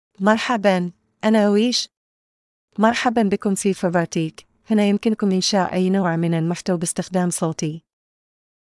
Aysha — Female Arabic (Oman) AI Voice | TTS, Voice Cloning & Video | Verbatik AI
Aysha is a female AI voice for Arabic (Oman).
Voice sample
Listen to Aysha's female Arabic voice.
Aysha delivers clear pronunciation with authentic Oman Arabic intonation, making your content sound professionally produced.